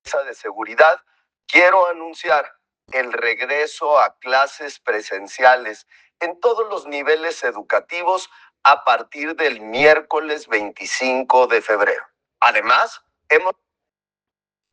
Guadalajara, Jalisco.- Debido a la situación del estado confirmada por la Mesa de Seguridad, la Secretaria de educación Jalisco ha indicado que se dió la instrucción de regresar clases presenciales el día de mañana 25 de Febrero. Habló Pablo Lemus, gobernador del estado.